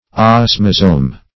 Search Result for " osmazome" : The Collaborative International Dictionary of English v.0.48: Osmazome \Os"ma*zome\, n. [Gr.